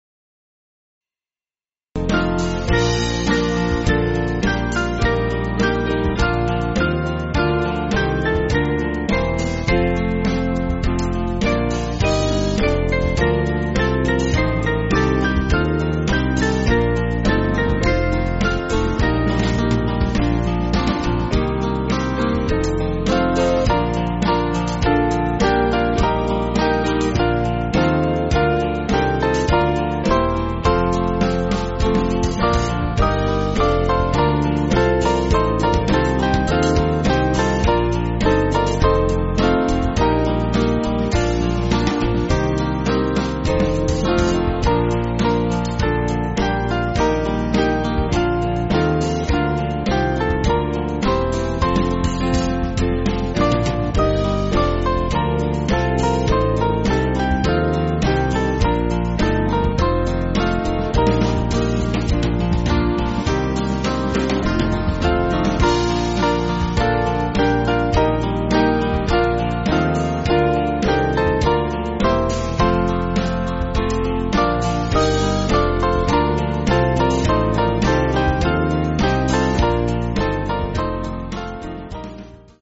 Small Band
(CM)   6/Bb